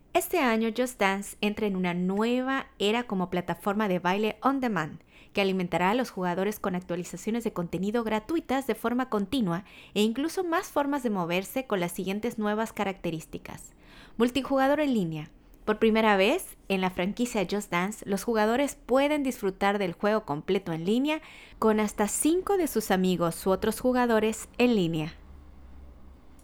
Prácticamente al grabar la voz obtenemos una reproducción parecida a la original lo cual demuestra que el MAONO AU-PM422 tiene muy buena sensibilidad (-47 dB) y amplio rango de frecuencia. Además, no se perciben los ruidos provenientes de otras partes.
MAONO-AU-PM422-Prueba-de-Voz-2.wav